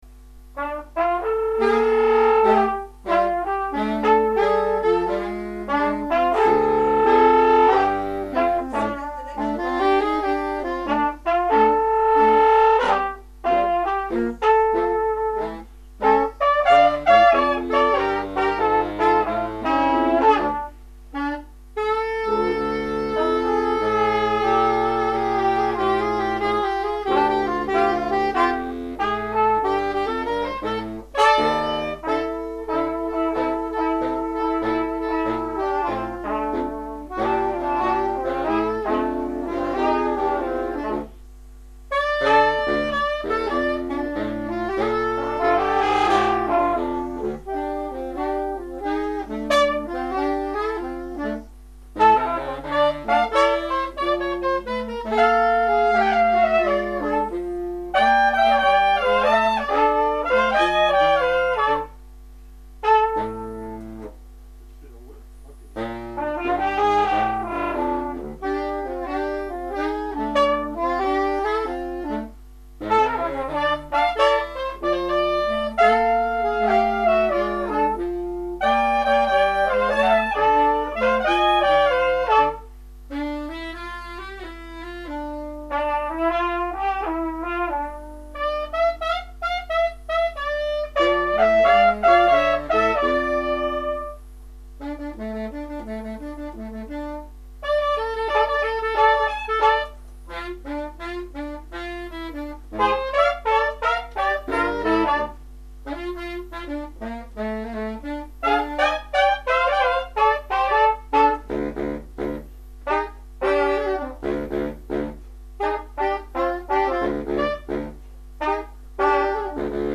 ss, bars, tp/flh, !perf
· Genre (Stil): Jazz